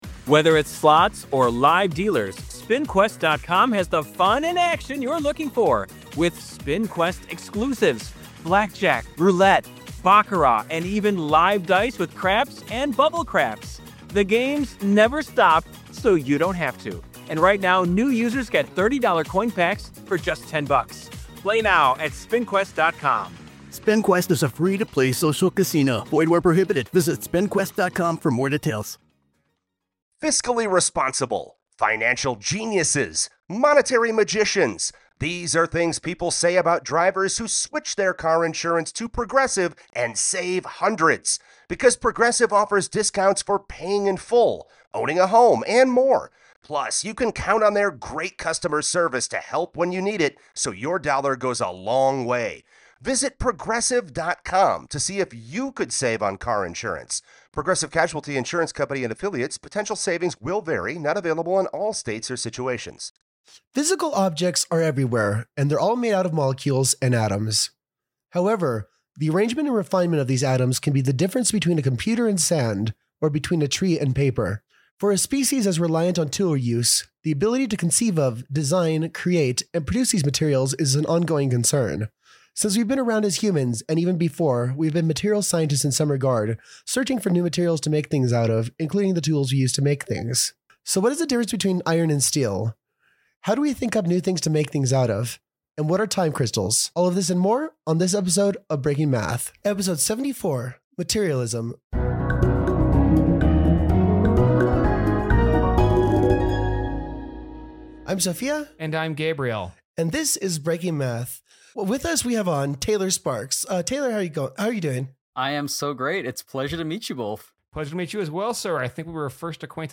73: Materialism: a Material Science Podcast Podcast Episode (Interview